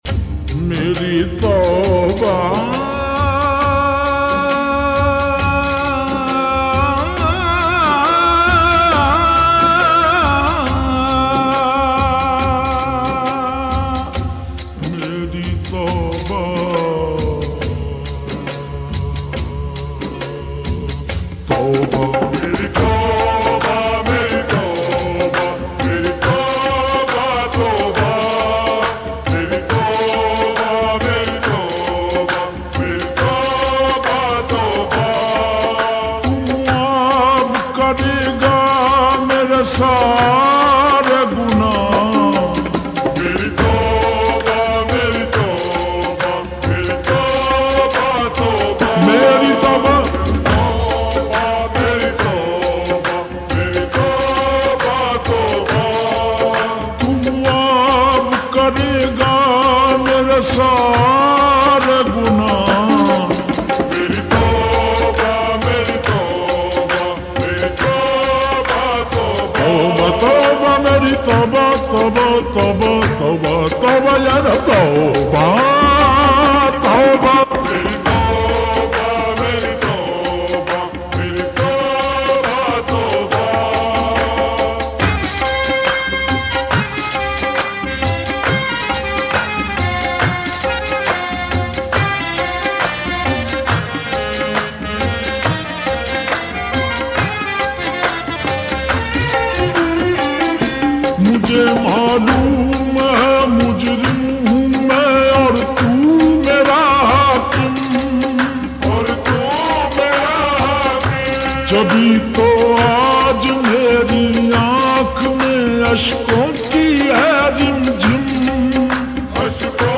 FULL NAAT (SLOW+REVERB)